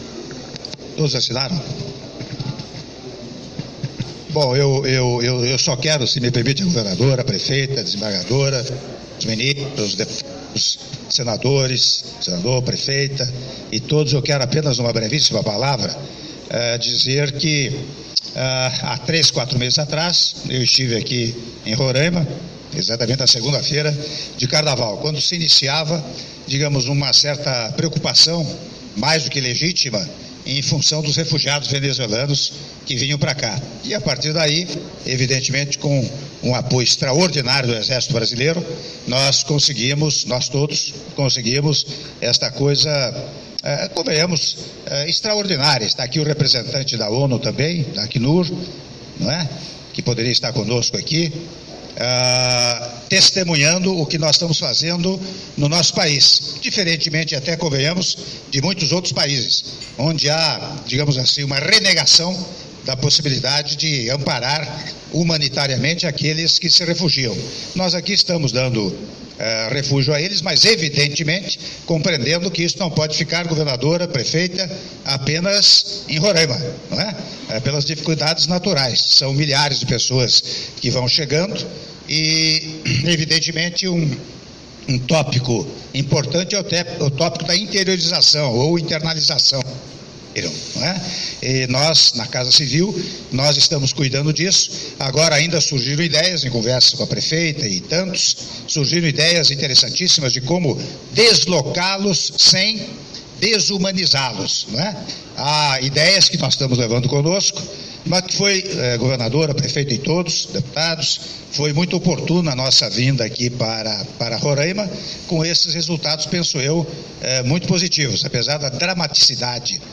Áudio do discurso do Presidente da República, Michel Temer, durante evento no Círculo de Oficiais de Boa Vista - Boa Vista/RR (03min25s)